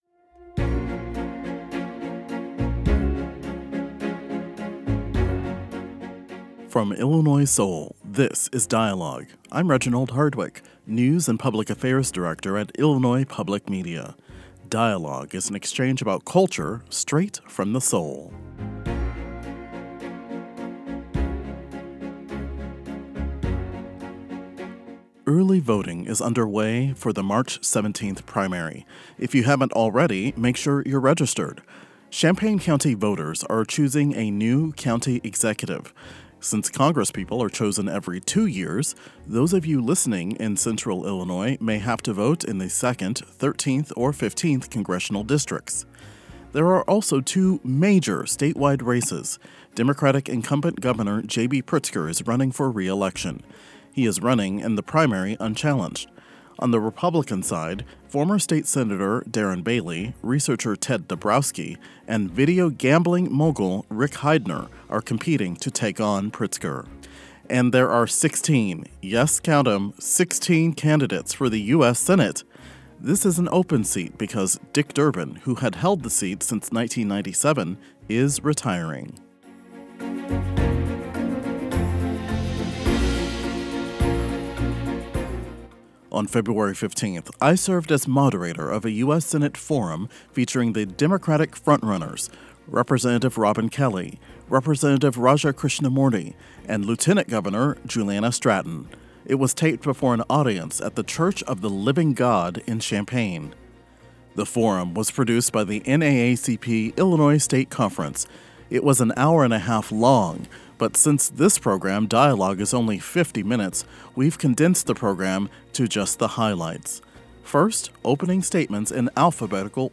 Over 100 people showed up at Love Corner Church in Champaign on February 15 to hear three Democratic candidates for U.S. Senate share their top priorities and answer questions from the audience.